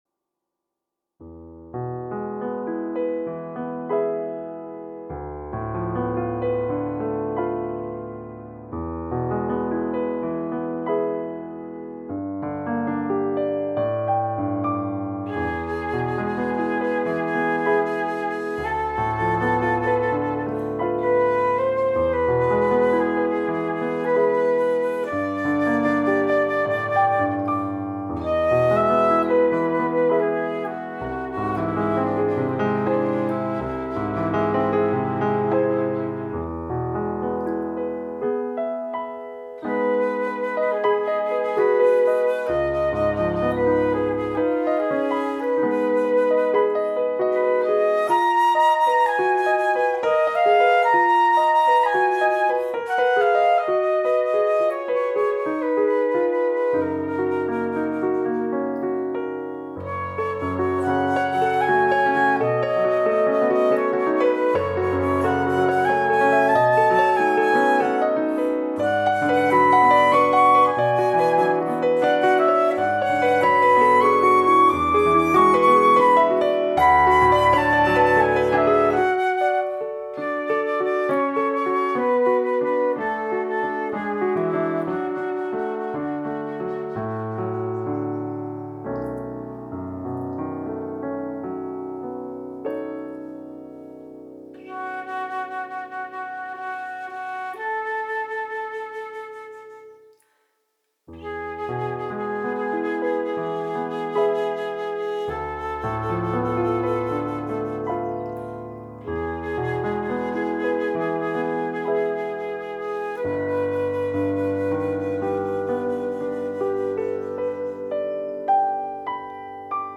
Although in-person collaboration poses certain risks at this time, multi-track recording provides a means for ensemble work to continue.
We played Jascha Heifetz’s transcription of the work for violin and piano, with a few adjustments.
This change enabled me to create a more subtle departure of the flute from the sound world of our performance.